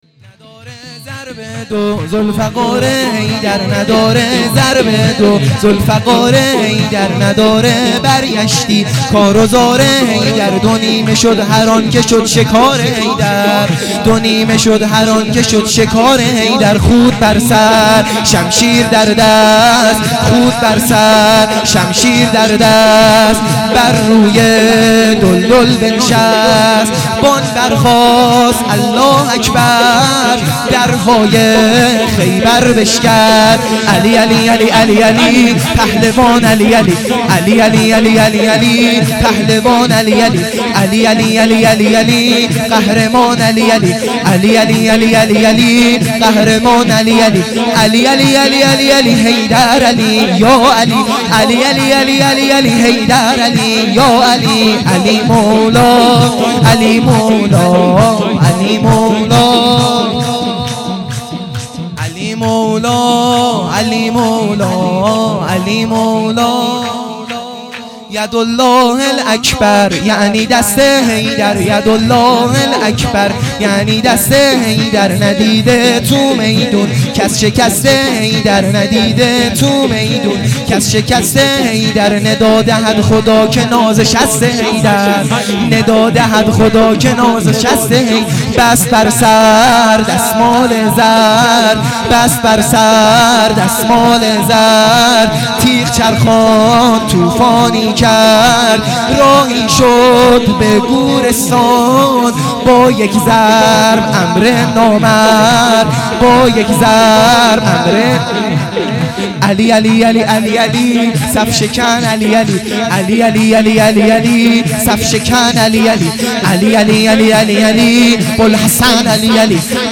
عید غدیر